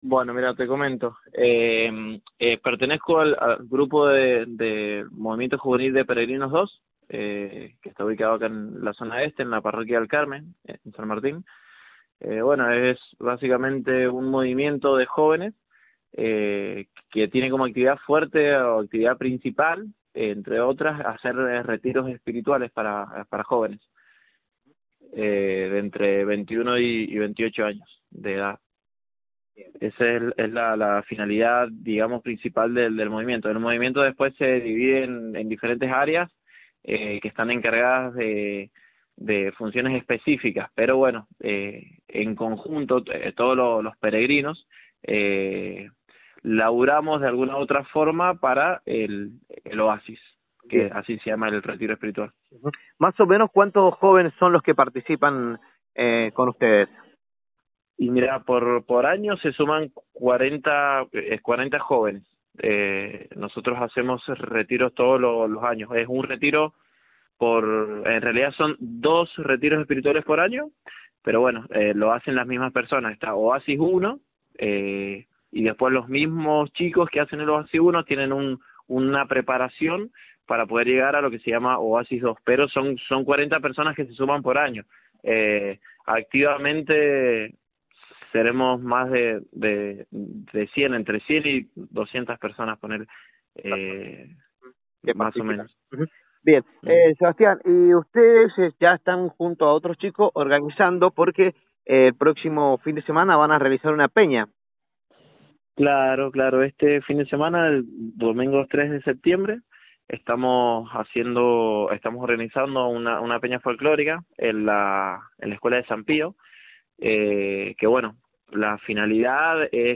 La mañana de la radio tuvo la voz de uno de los organizadores de la peña folclórica, que se aproxima en este fin de semana para la zona Este.